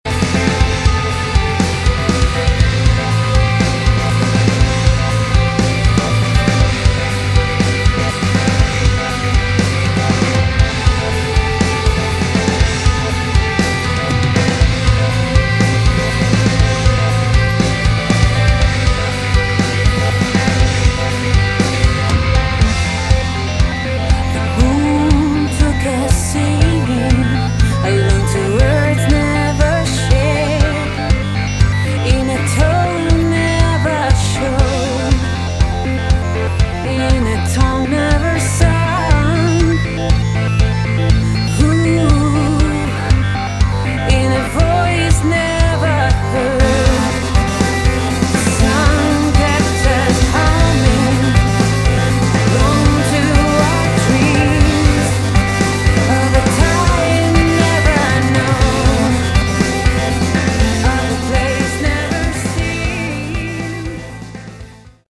Category: Modern Hard Rock/Metal
vocals
guitars, bass, keyboards